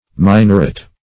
Search Result for " minorat" : The Collaborative International Dictionary of English v.0.48: Minorat \Mi`no*rat"\, n. [G. Cf. Minor , a.]
minorat.mp3